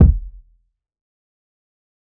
TC2 Kicks14.wav